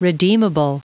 Prononciation du mot redeemable en anglais (fichier audio)
Prononciation du mot : redeemable